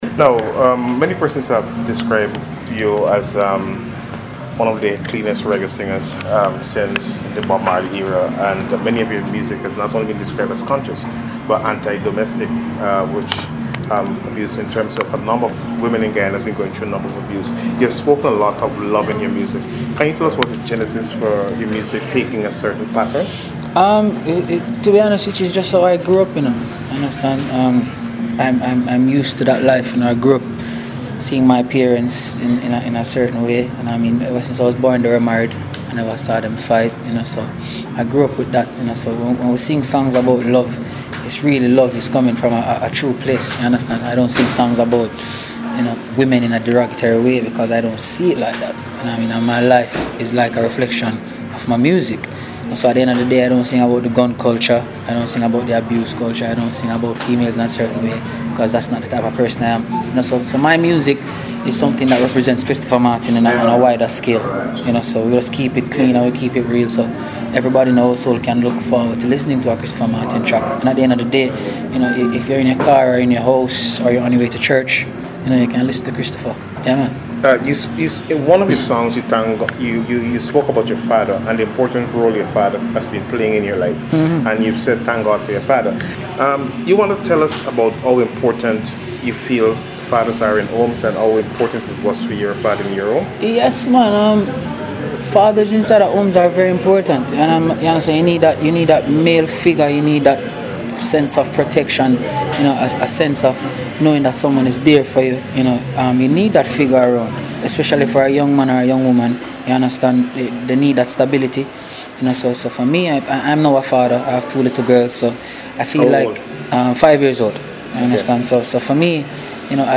Here is a part of that interview.
Interview-with-Christopher-Martin.mp3